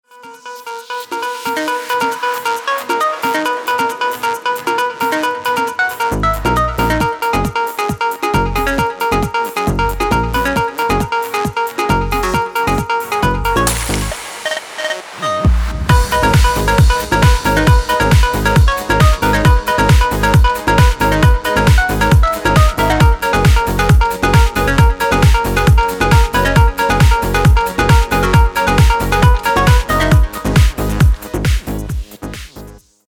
بی کلام رینگتون موبایل